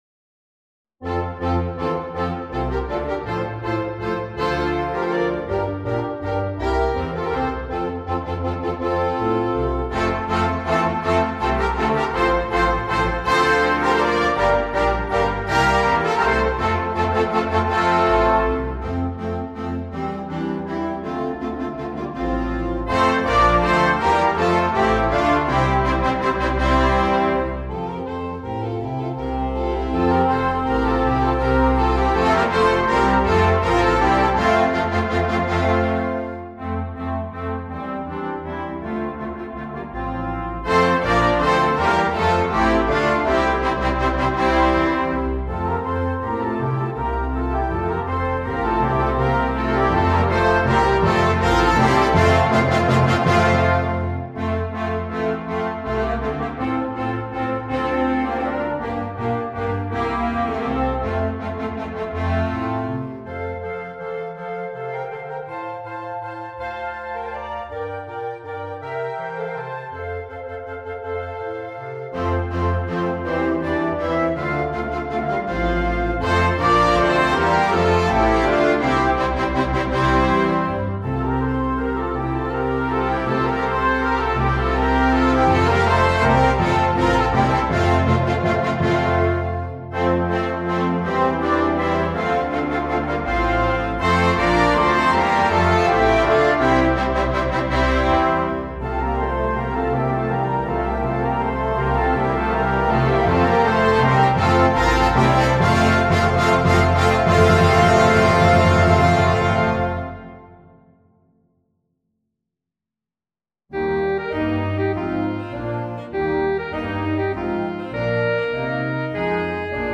Gattung: Suite für Blasorchester
Besetzung: Blasorchester
Allegro – Andante – Allegro